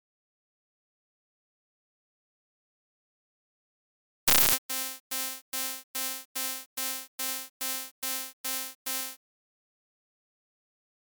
以下の例では、毎秒1.2ビートの繰り返しをテンポとして設定し、 -5秒(過去に5秒遡る)の時点から半分の大きさでビートを刻み、 「現在」に来たところで大きく鳴って、5秒が経過したところでストッブする。